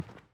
Sounds / sfx / Footsteps / Carpet / Carpet-02.wav
Carpet-02.wav